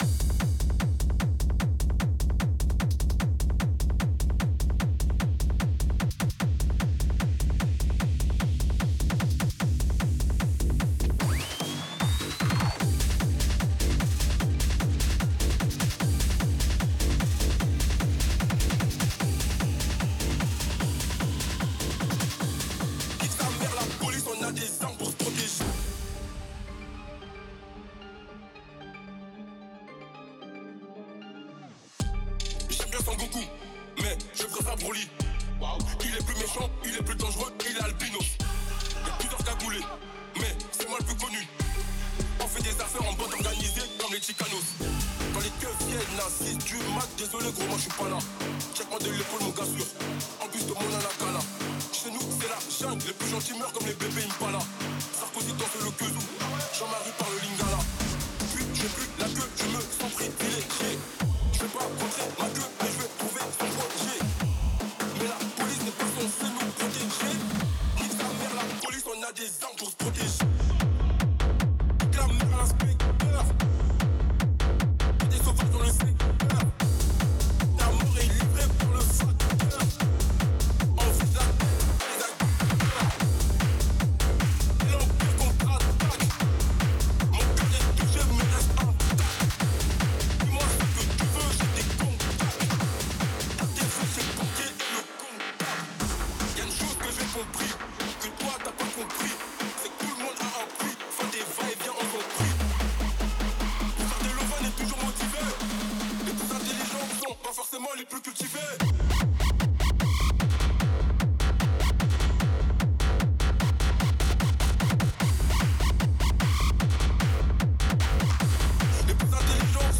Mixage Techno
Après Mixage
🎵 Techno / Rave / Tekno